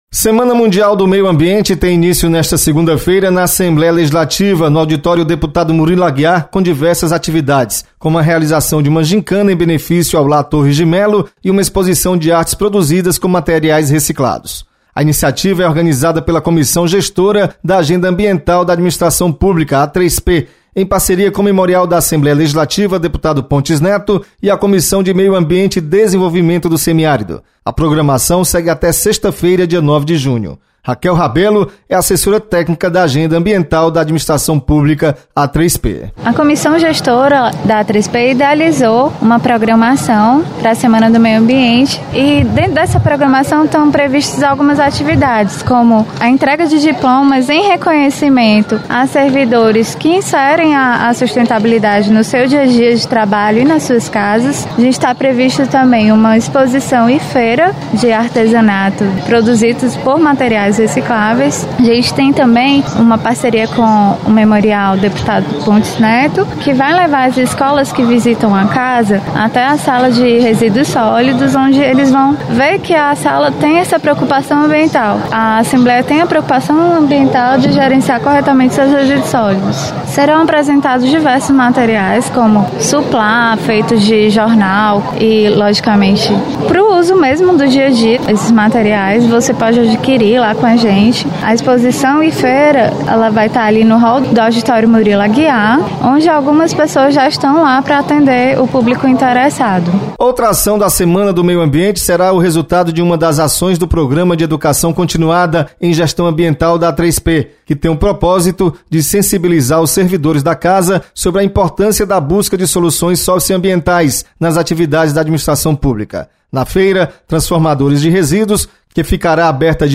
Você está aqui: Início Comunicação Rádio FM Assembleia Notícias Meio Ambiente